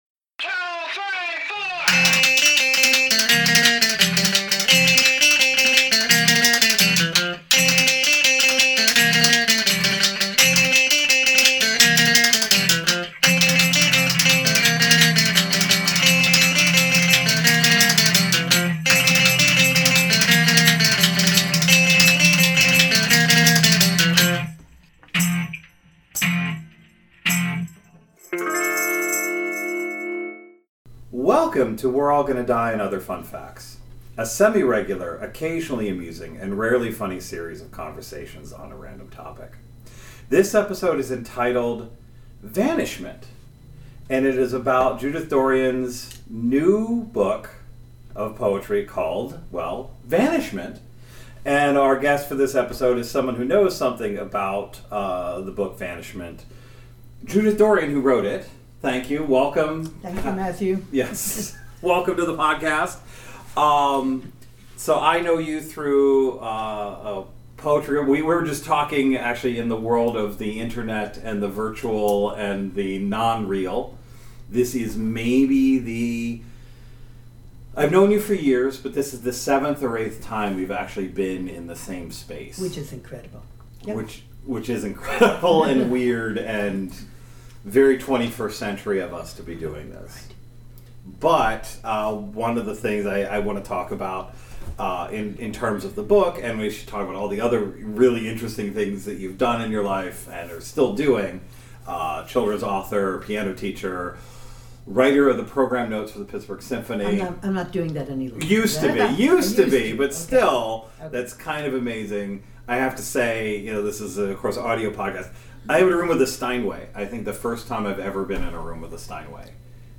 a conversation about poetry, play, music, memory, and what’s worth holding onto.